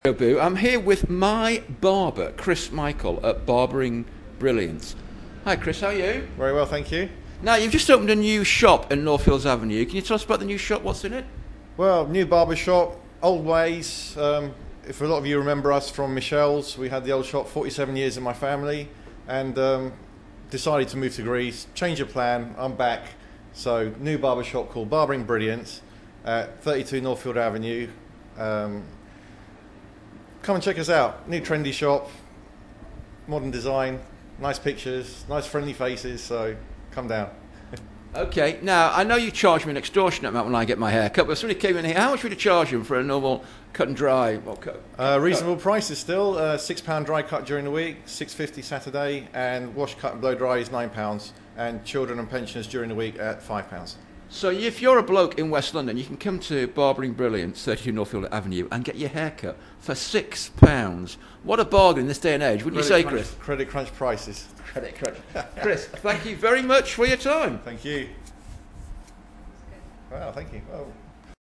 Interview with my barber